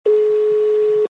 Download Call sound effect for free.
Call